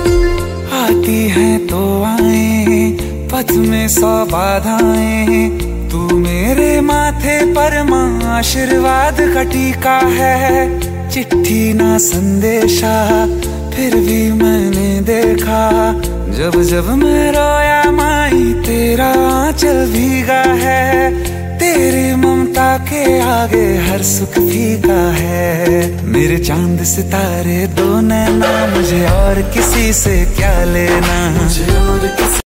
Bhakti Ringtones Devotional Ringtones